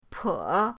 po3.mp3